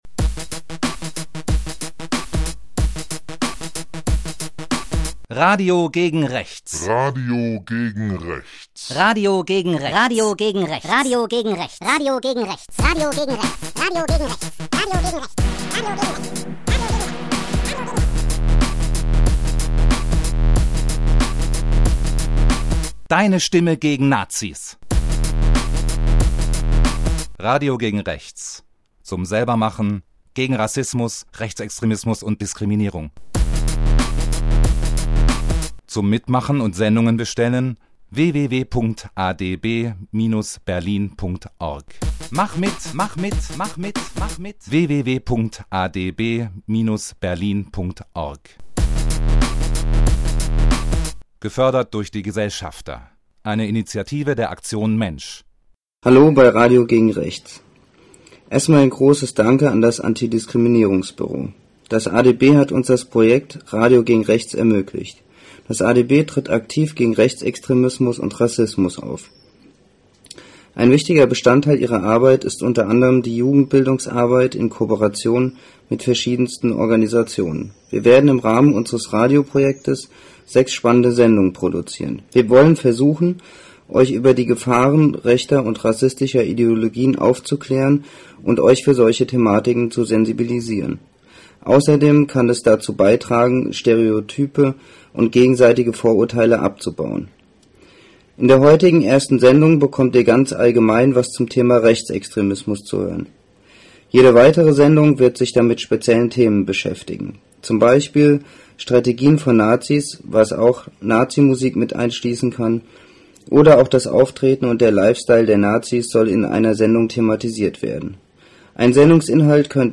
Konkrete Sendungen: 1. Sendung: „Was ist Rechts?“ Die erste Sendung sollte in das Projekt vorstellen und in das Thema Rechtsextremismus einführen. Nachdem sich in der Gruppe über das Thema informiert wurde, wurde ein Interview dazu geführt. Dieses wurde im Tonstudio des „Freibeuterschiff“ aufgenommen und in den nächsten Treffen gemeinsam geschnitten und anschließen An- und Abmoderationstexte eingesprochen.